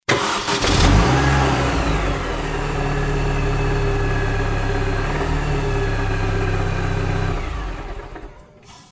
Звуки двигателя автомобиля